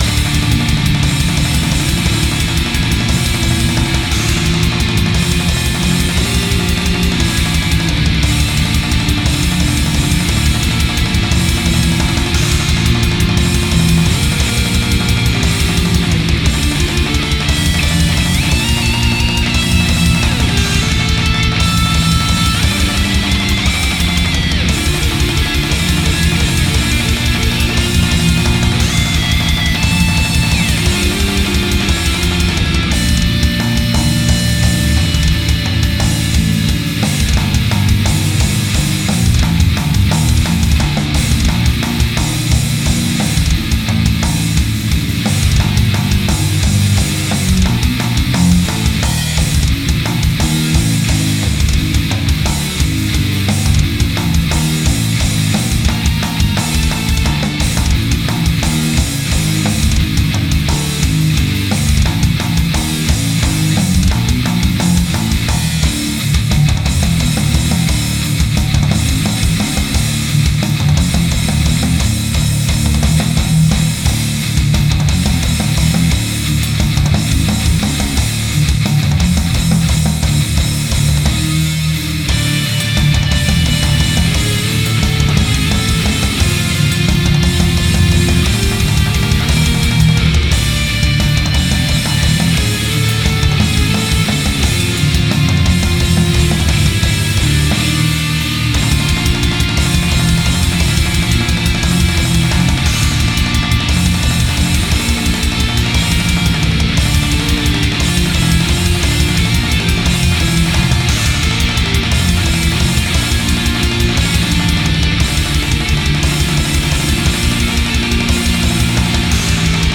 Гитары
Бас-гитара, программирование ударных